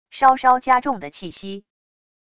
稍稍加重的气息wav音频